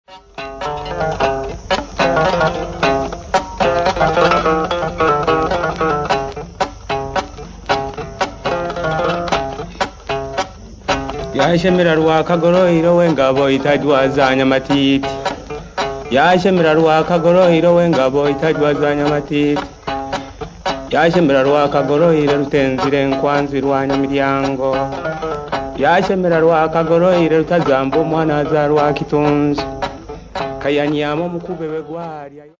Music--Uganda
Folk music
Field recordings
sound recording-musical
She called to Kitungi to come to her like the wind over the lake. A recitative Legend, with Nanga trough zither, 7 notes.